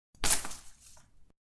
Звук падения одного цветка на пол